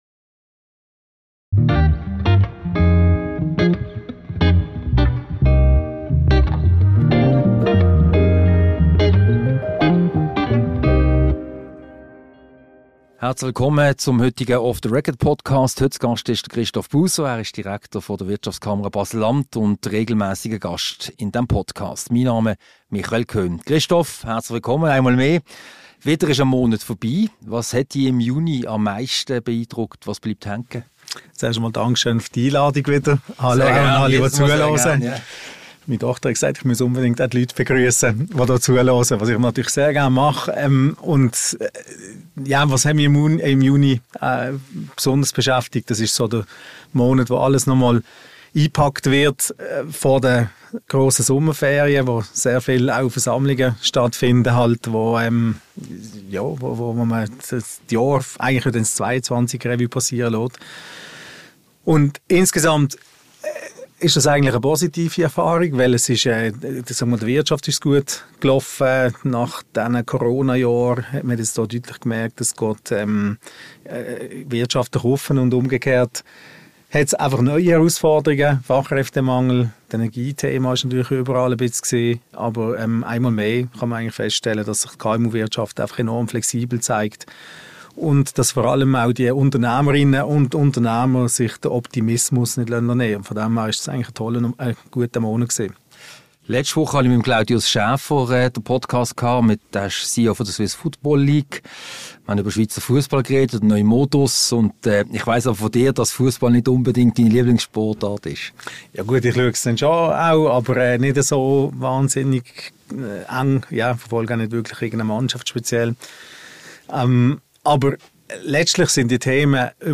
Ein Gespräch über die regionalen Wirtschafts- und Politthemen, die Baselbieter Politik sowie ein Blick auf die Berufsbildung und die kommende Berufsschau.